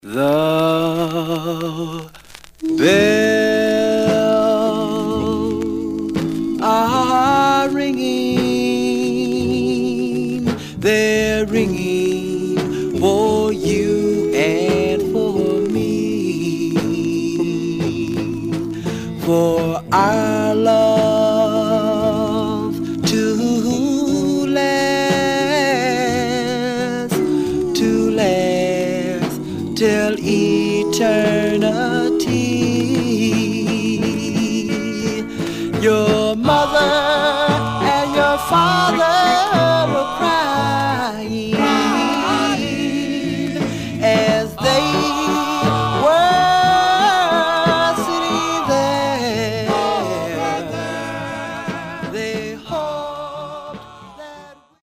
Stereo/mono Mono
Male Black Group Condition